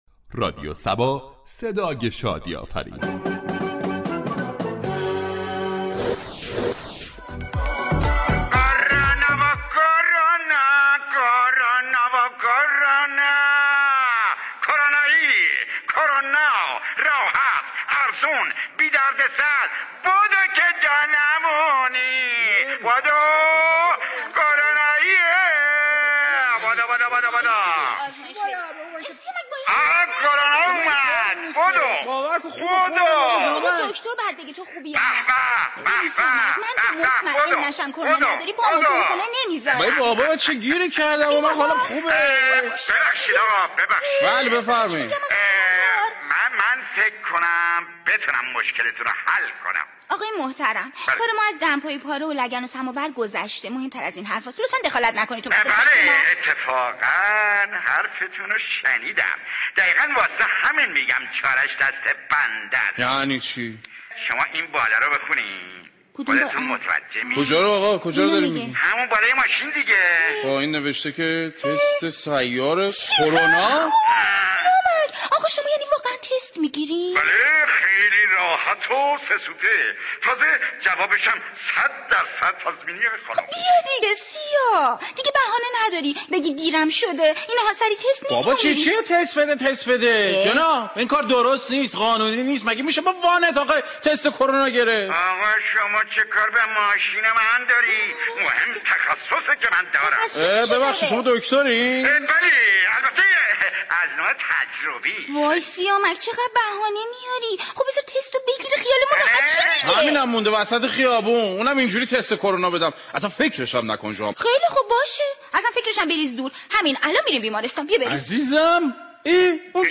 بشنوید از روش های خلق الساعه تست كرونا در بخش نمایشی برنامه